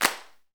PRC XCLAP0NL.wav